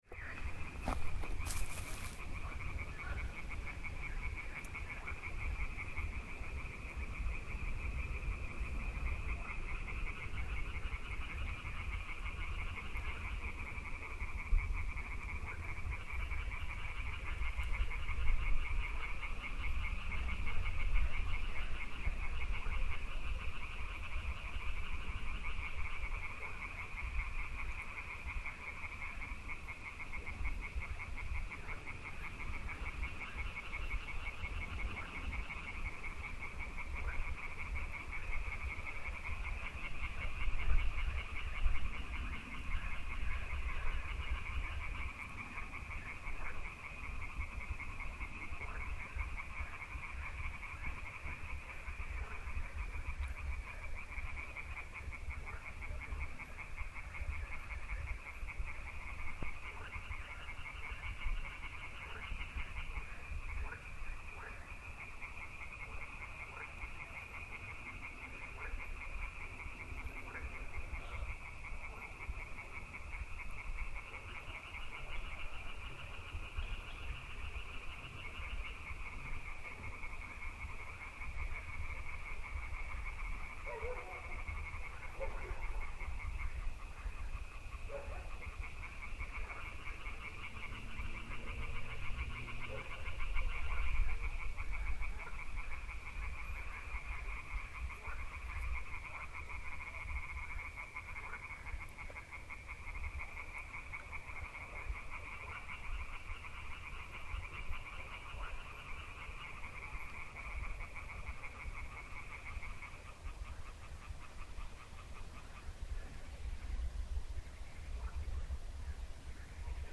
Countryside frogs
There must be some other creatures sounding out with the frogs. I spot the obvious dog. Somekind of insects maybe? Too bad I’m lousy with birds so I can’t recognize the ones on this recording. An owl? I try to count the frogs when they stop for awhile and then start again, there is at least four. But there must be more hidden in the undergrowth…
Listen to the croaking: